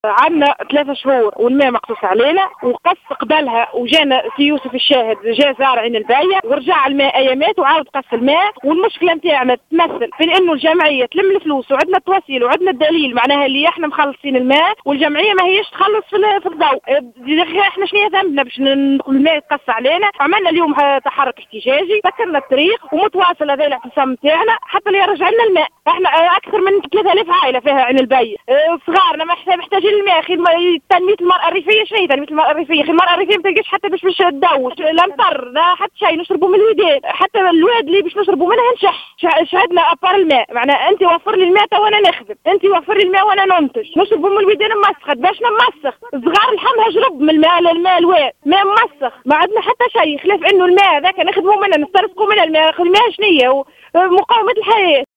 Une manifestante t√©l√©charger partager sur